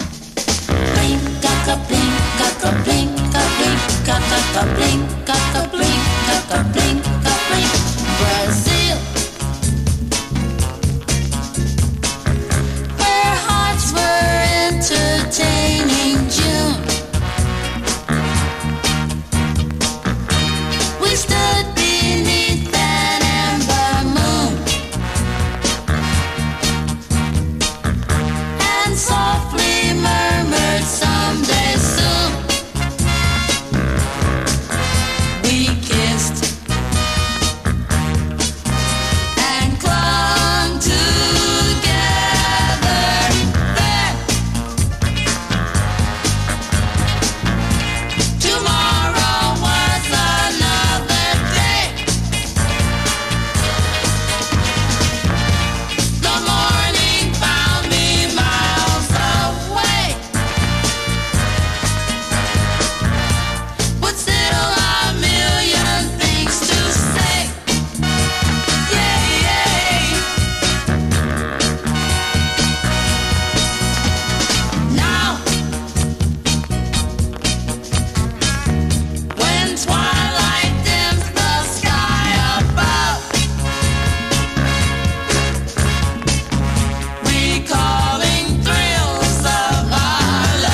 シンシナティ出身のガールズ・トリオ。